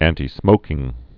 (ăntē-smōkĭng, ăntī-)